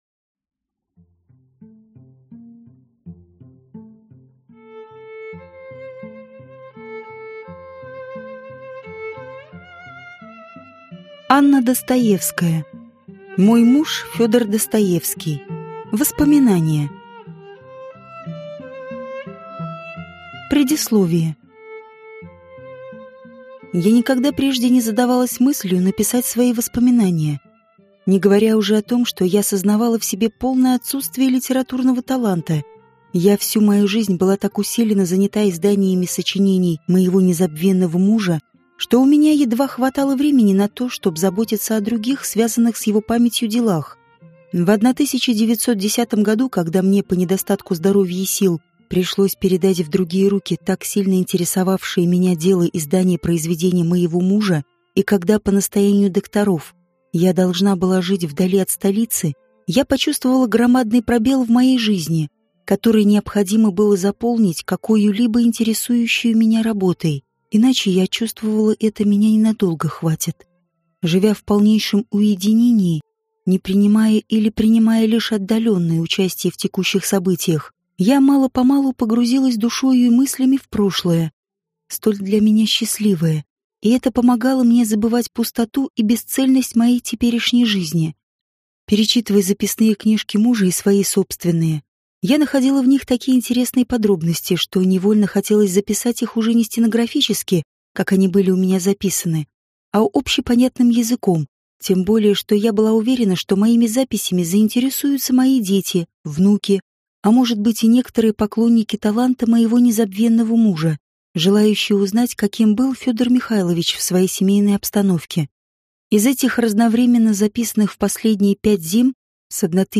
Аудиокнига Мой муж – Федор Достоевский. Жизнь в тени гения | Библиотека аудиокниг